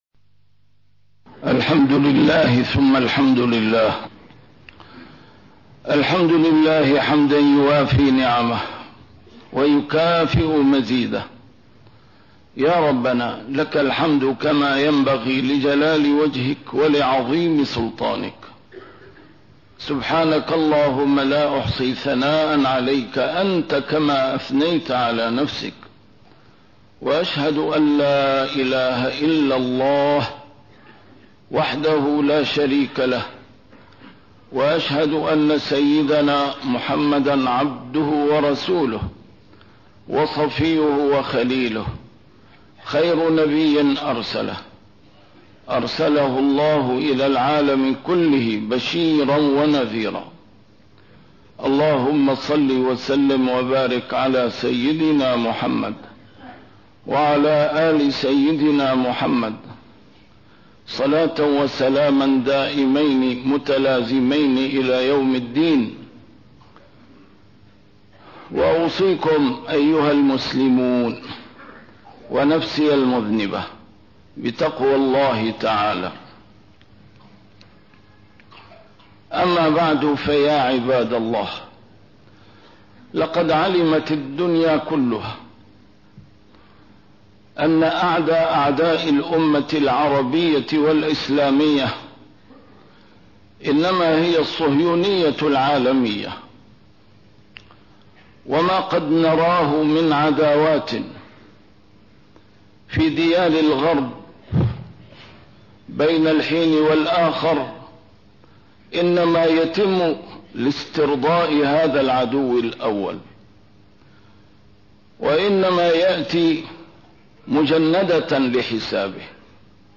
A MARTYR SCHOLAR: IMAM MUHAMMAD SAEED RAMADAN AL-BOUTI - الخطب - تماسك الأسرة المسلمة سرّ قوة الأمة